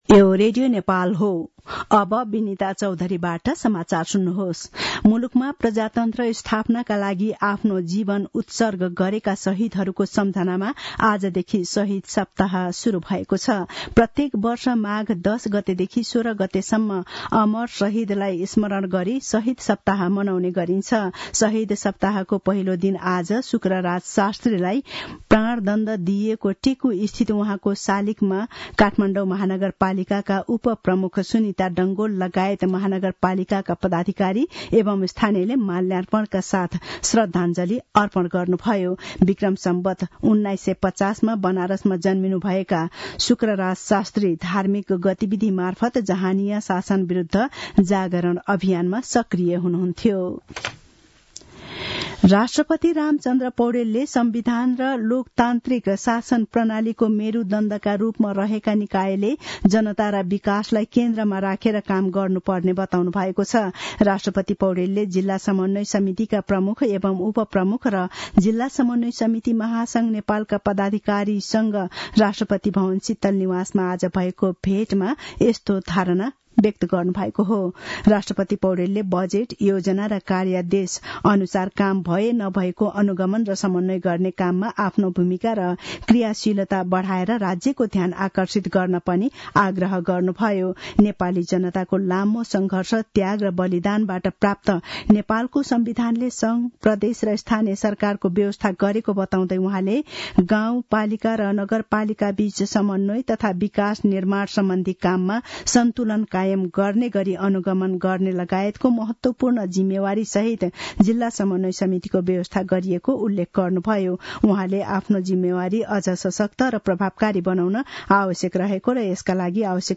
मध्यान्ह १२ बजेको नेपाली समाचार : ११ माघ , २०८१